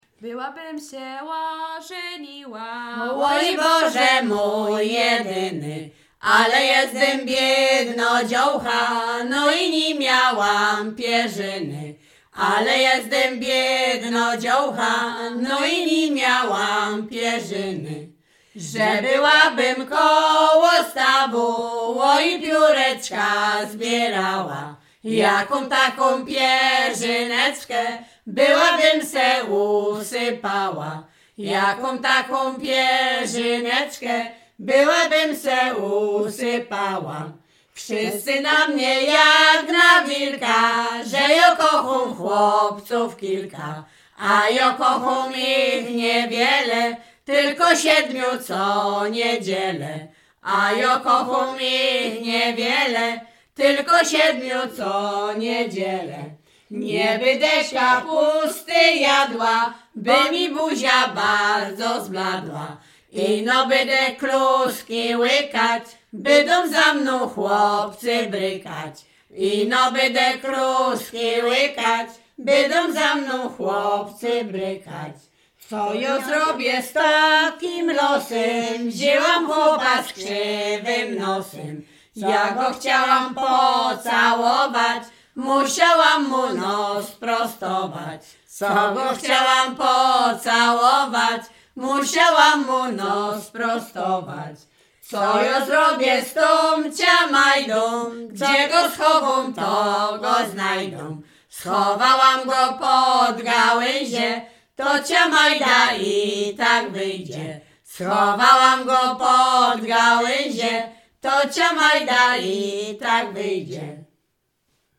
Śpiewaczki z Mroczek Małych
Sieradzkie
Przyśpiewki
weselne przyśpiewki żartobliwe